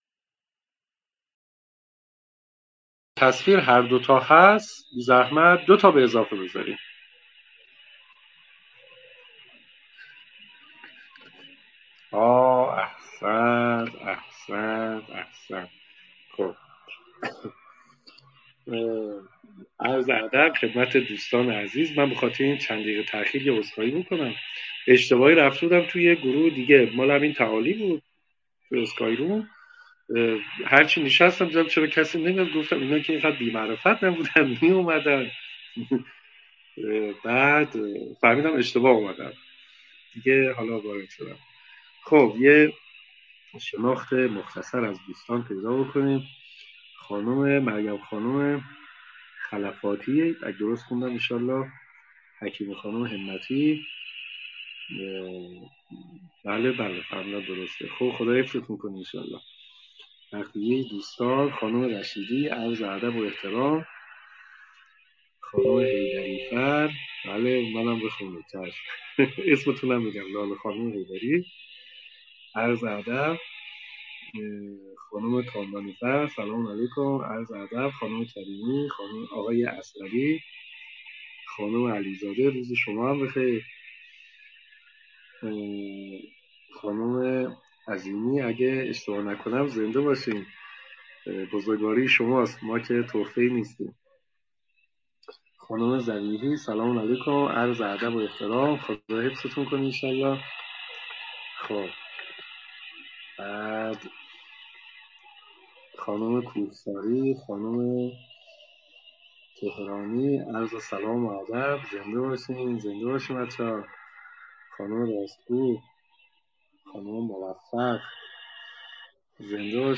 مقدمه تا بی نهایت - جلسه-پرسش-و-پاسخ_مبحث-هستی-شناسی